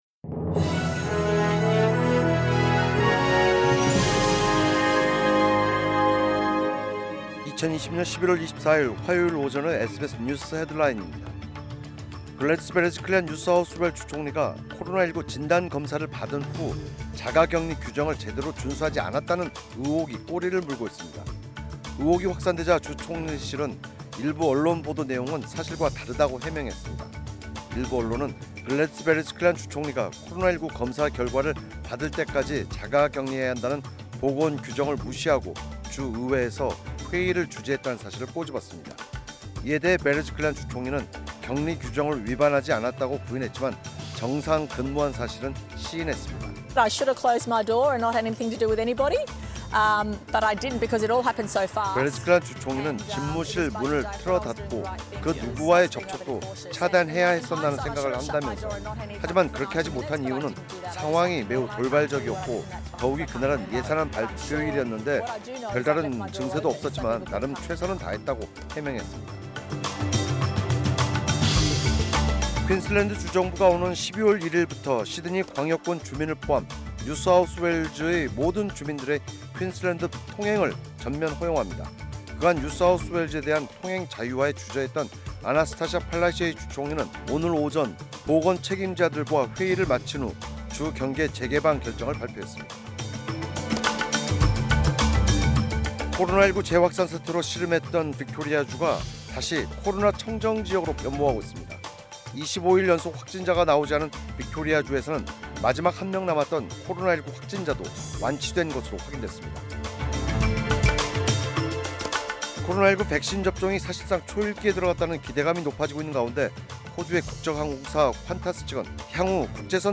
2020년11월 24일 화요일 오전의 SBS 뉴스 헤드라인입니다.